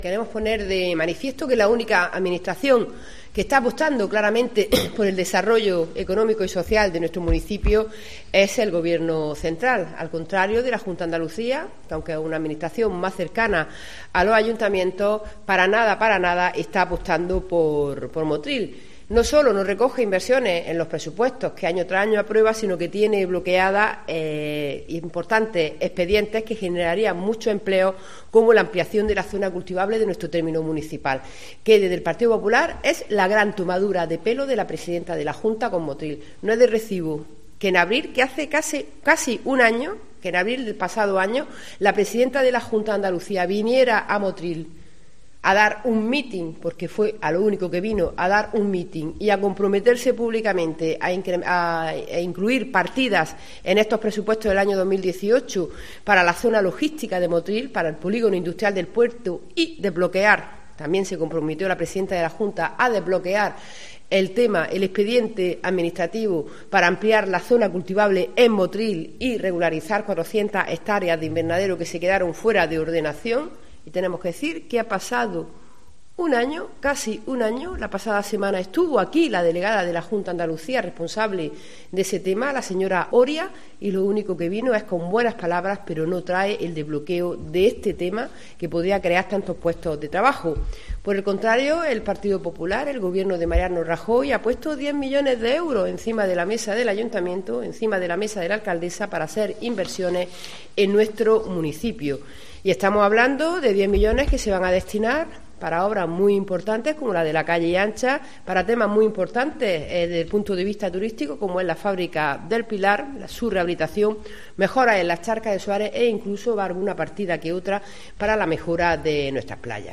La portavoz del PP de Motril y Diputada provincial, Luisa García Chamorro, habla hoy sobre las promesas incumplidas en materia de empleo por parte de la Junta de Andalucía y por el Ayuntamiento de Motril.